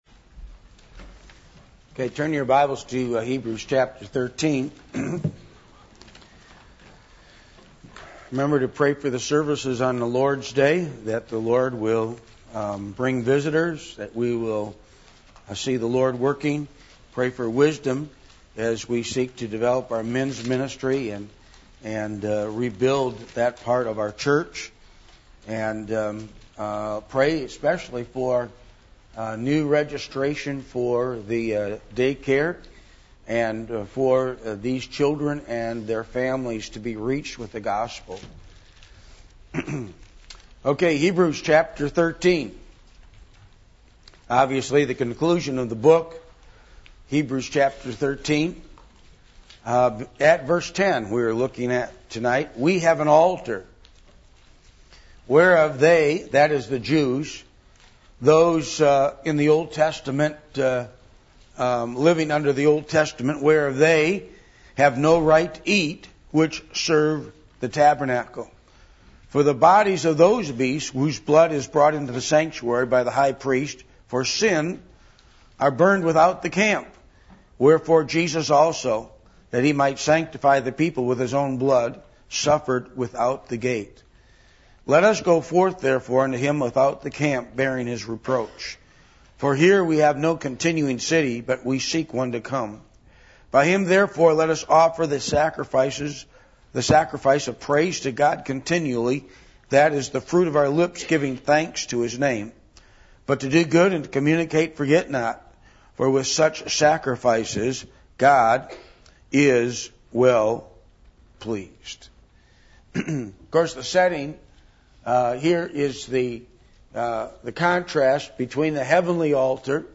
Passage: Hebrews 13:10-16 Service Type: Midweek Meeting %todo_render% « Preparing For The Last Days The Attributes Of God